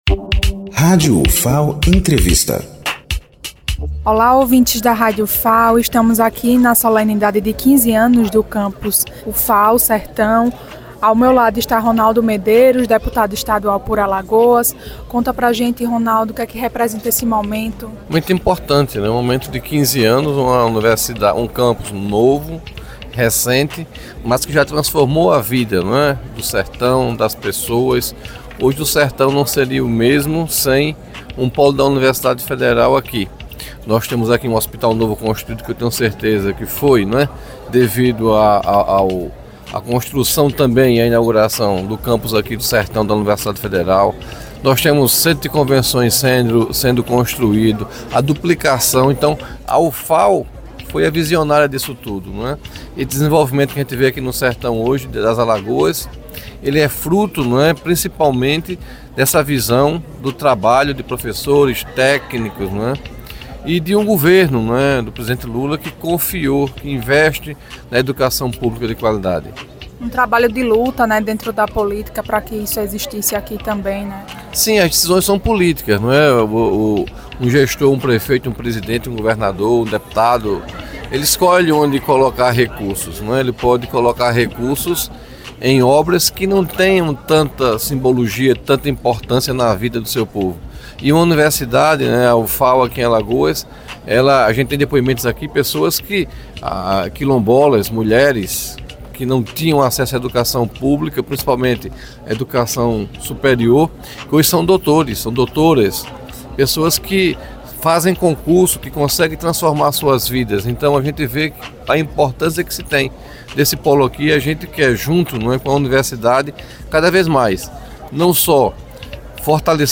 O deputado estadual Ronaldo Medeiros participou da solenidade comemorativa
Entrevista
RonaldoMedeiros.mp3